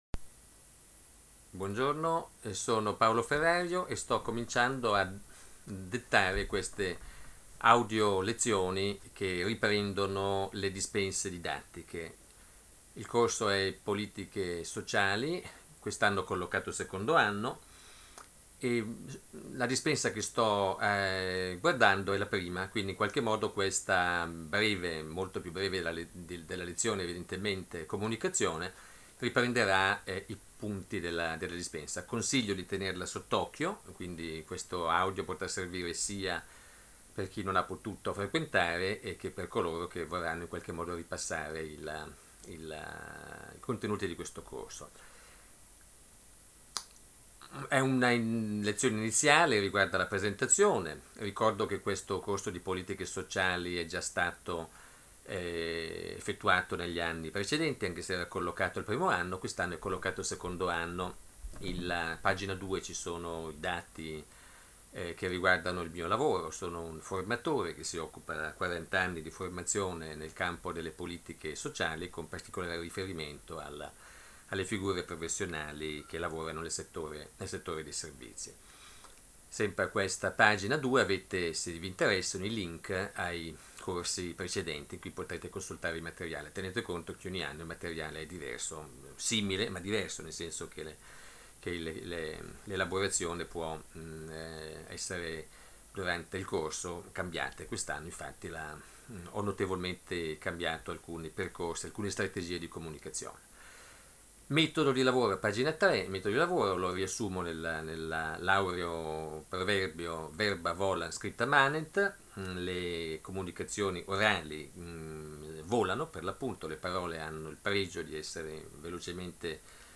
per scaricare l’audiolezione in formato mp3: Audio Lezione su RIFORMA COSTITUZIONALE N. 3/2001, per l’analisi delle politiche sociali